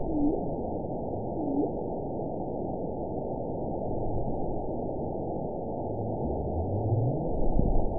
event 919786 date 01/23/24 time 14:43:45 GMT (1 year, 10 months ago) score 8.70 location TSS-AB05 detected by nrw target species NRW annotations +NRW Spectrogram: Frequency (kHz) vs. Time (s) audio not available .wav